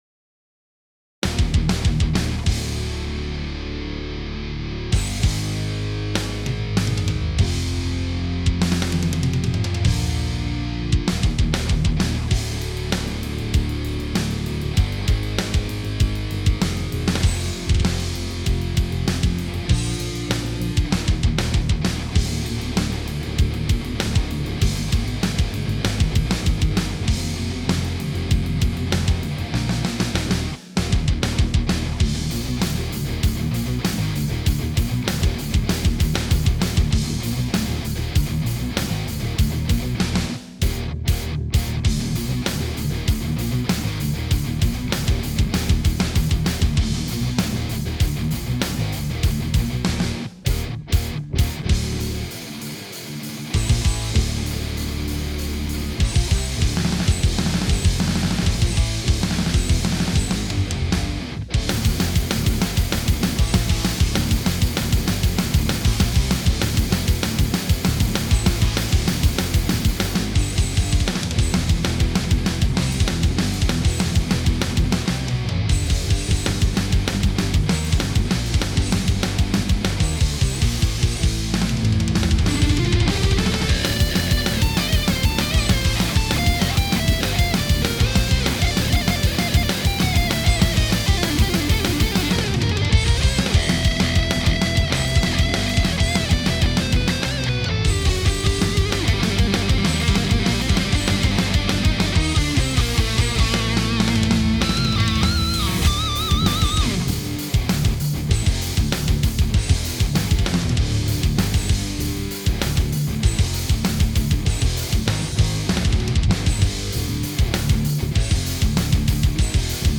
No Compression